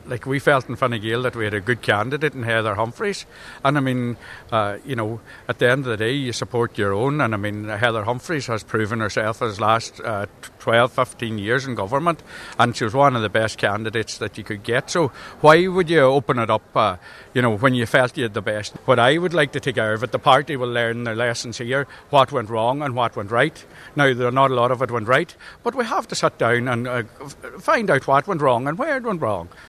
Donegal Senator Manus Boyle has defended the party’s decision to block councillors voting for Independent candidates and says lessons need to learned from the election: